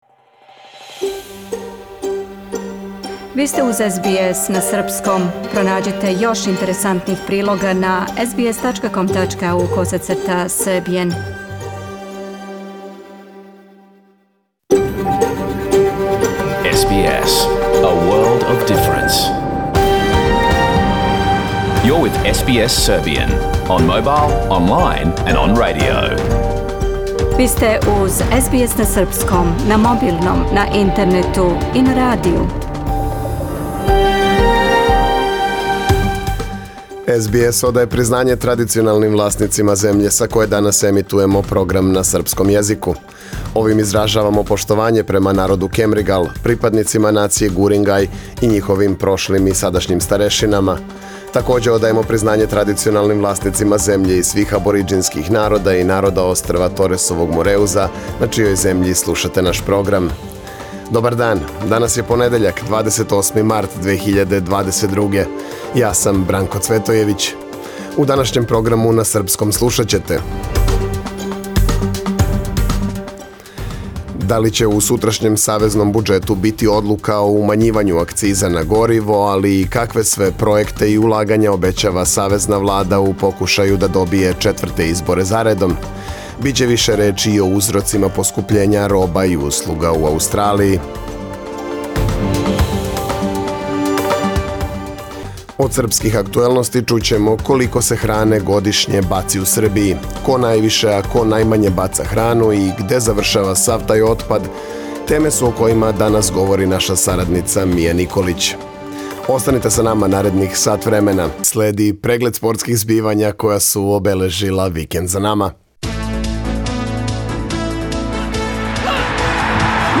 Програм емитован уживо 28. марта 2022. године
Ако сте пропустили нашу емисију, сада можете да је слушате у целини као подкаст, без реклама.